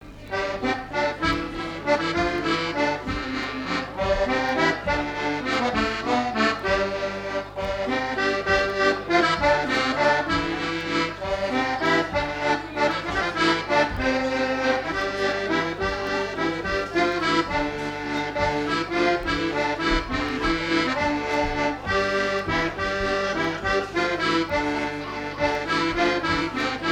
Valse
danse : valse
Fête de l'accordéon
Pièce musicale inédite